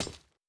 Grenade Sound FX
Impact on Concrete.wav